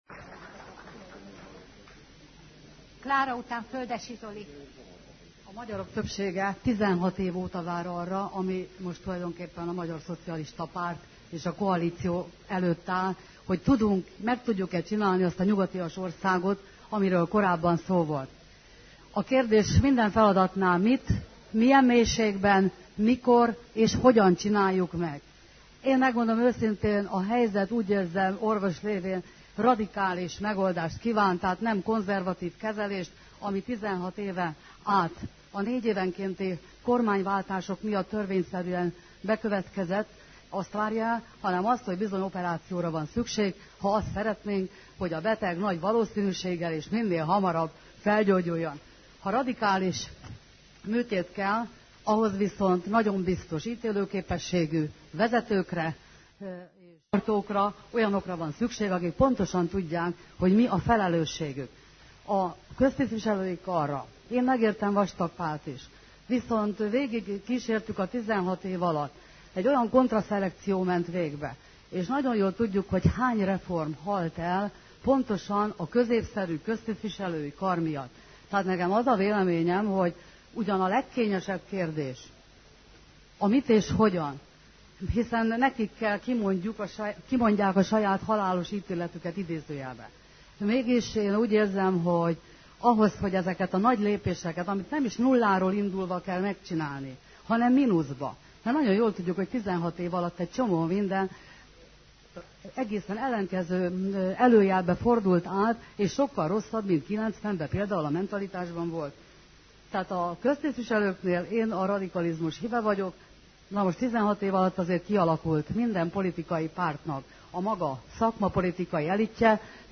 Perjési balatonőszödi beszéde
A 2006. május 26-kai frakcióülésen, az ominózus Gyurcsány-beszéd után több szoci politikus is szót kért.